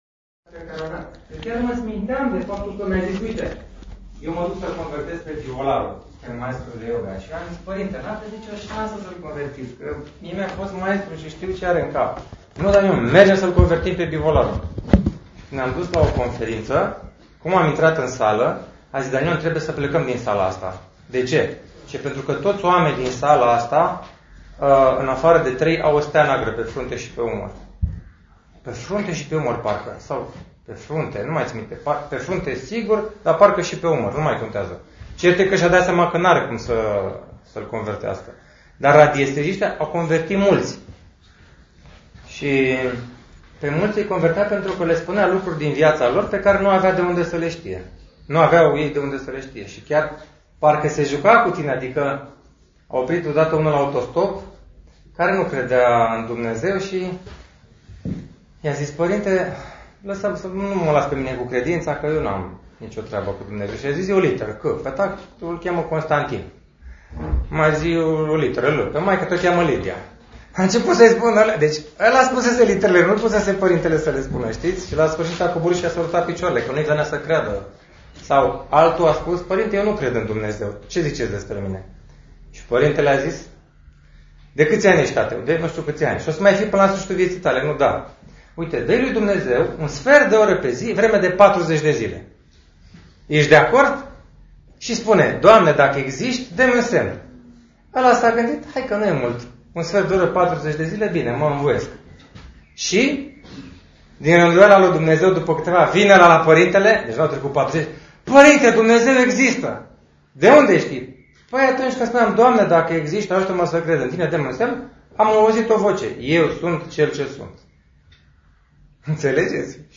Întâlnirea a avut loc la Bisericuță